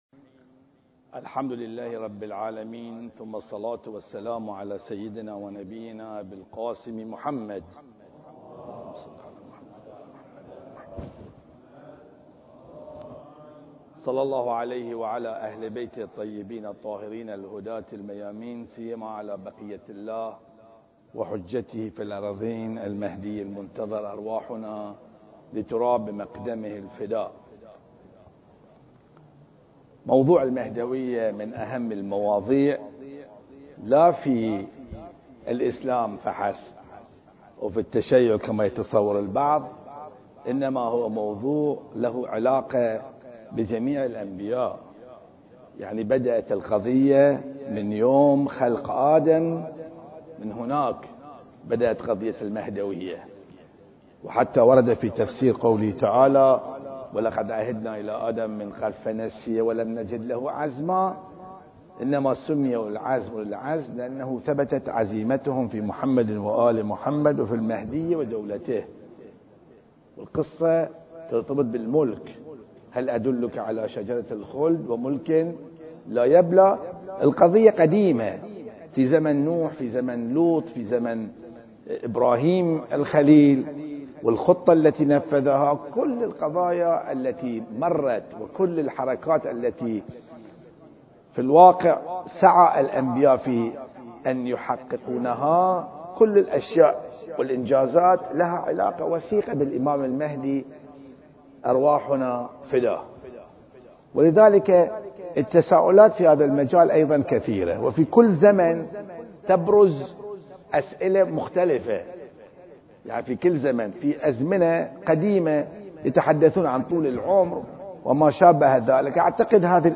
أسئلة مهدوية (1) المكان: مسجد الغدير - البحرين التاريخ: 1442 للهجرة